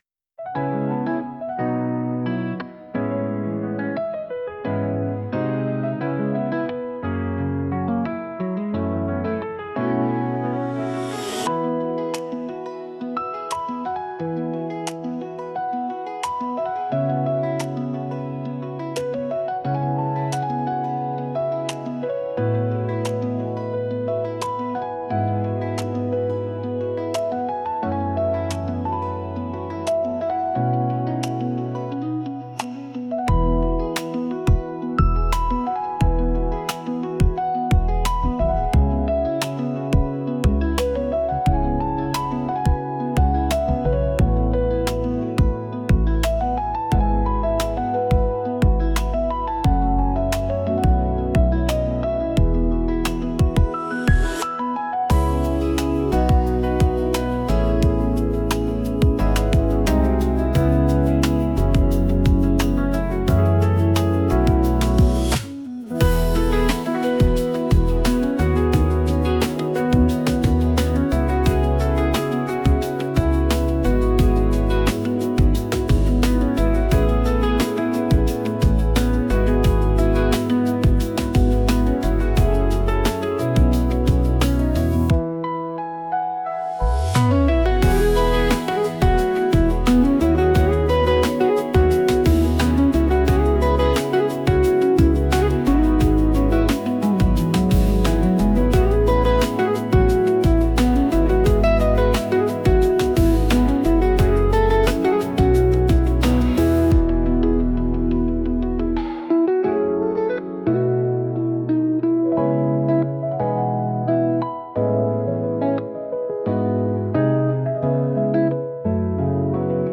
ノスタルジック 懐かしい 青春